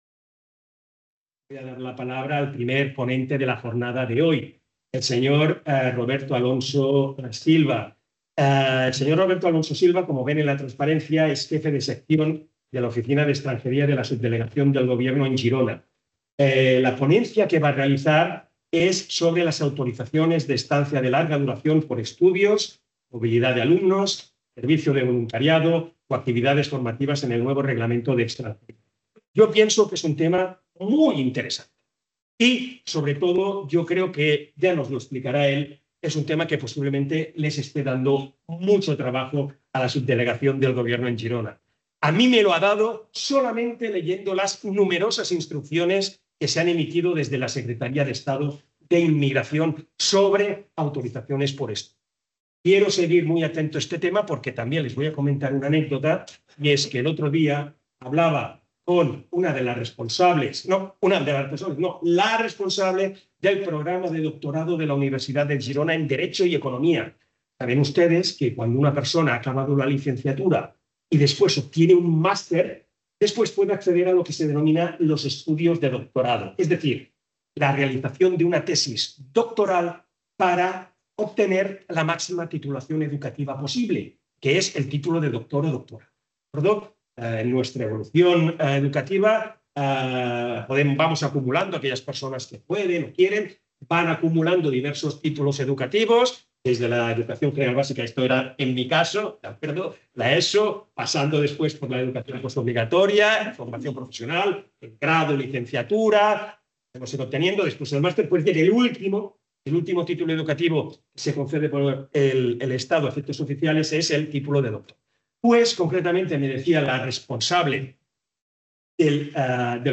In the third conference on the New Immigration Regulations organized by the Chair of Immigration, Rights and Citizenship of the University of Girona
En les terceres jornades sobre el Nou Reglament d'Estrangeria organitzades per la Càtedra d'Immigració, Drets i Ciutadania de la Universitat de Girona